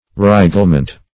Search Result for " reiglement" : The Collaborative International Dictionary of English v.0.48: Reiglement \Rei"gle*ment\ (-ment), n. [See Reglement .]
reiglement.mp3